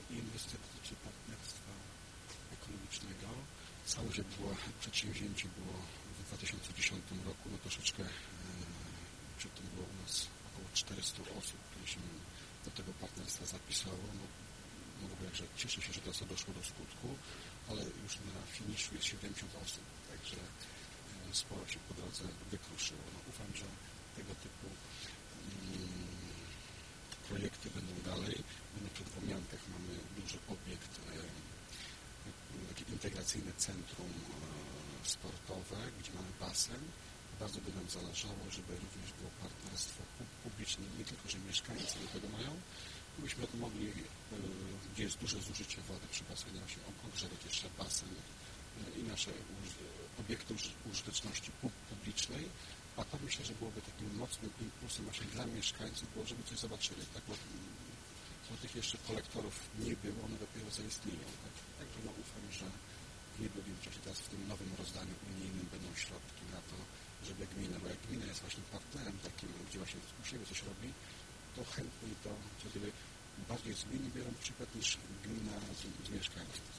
Wypowiedź burmistrza Łomianek, Tomasza Dąbrowskiego